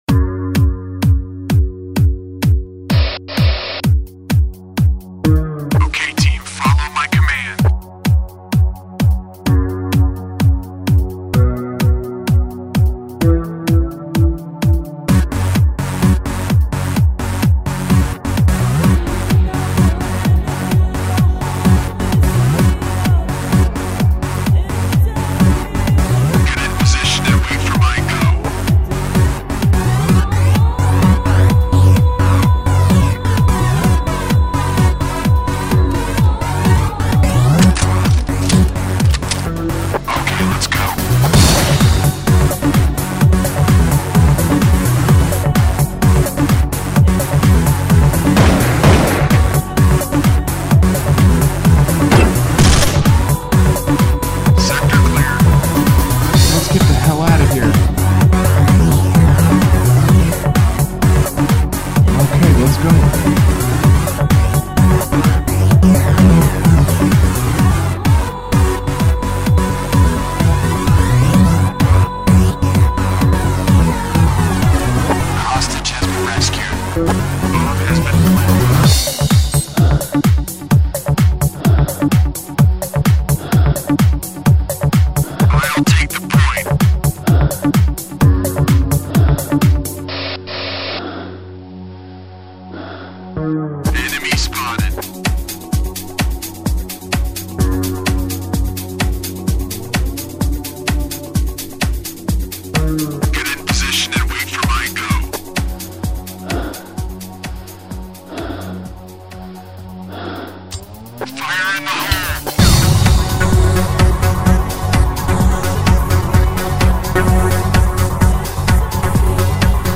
74.35 Всем поклонникам «Counter- Strike» посвящается боевой микс, естественно со звуками из самой игры.